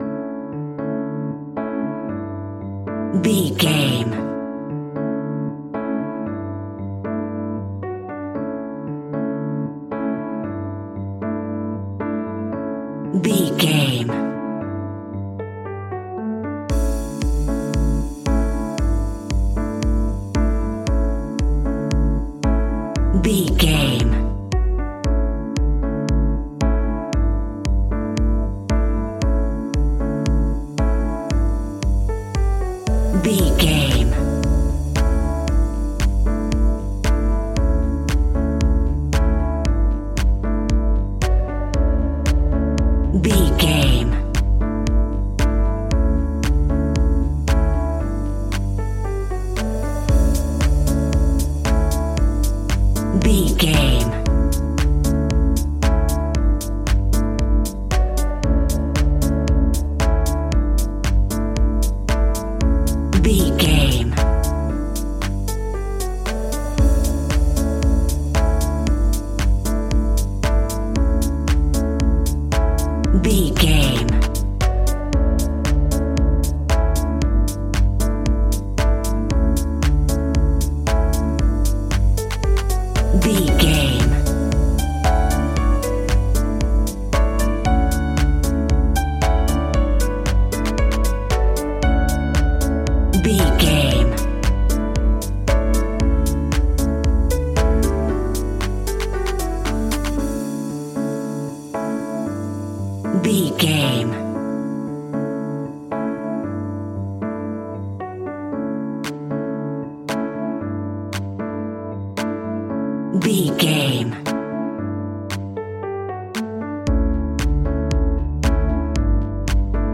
Ionian/Major
D
uplifting
energetic
bouncy
electric piano
drum machine
synthesiser
funky house
synth bass